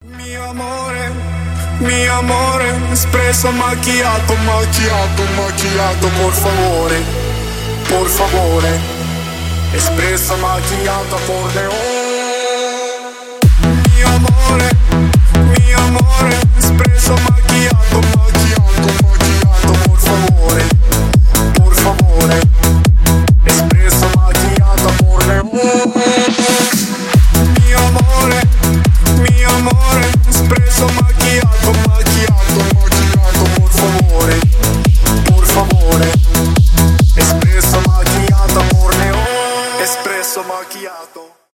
танцевальные , техно
ремиксы